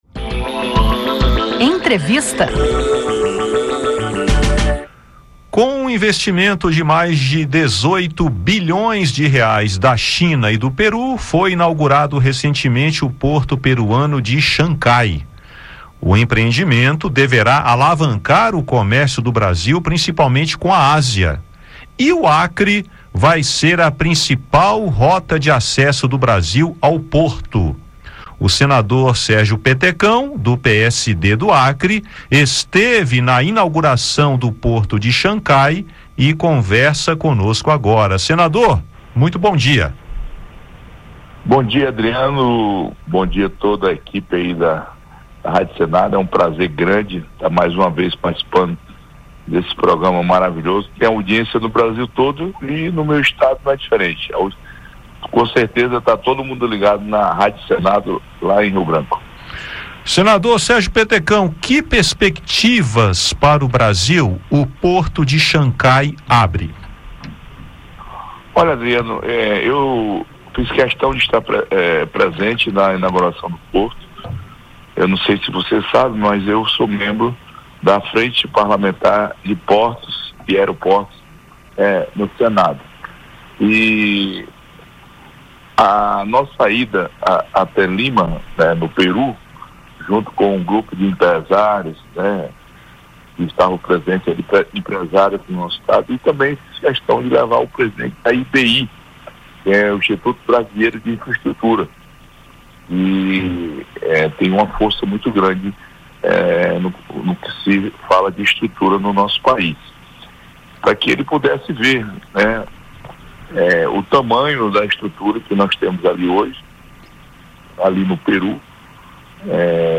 Em entrevista, Petecão fala das perspectivas para o Acre com essa nova rota comercial e o incremento do comércio entre Brasil, Peru e Ásia.